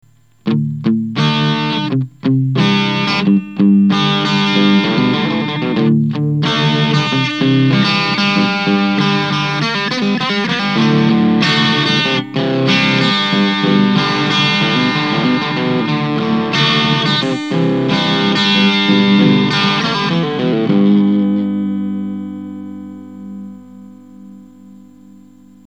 single bridge
TS808_single_bridge.mp3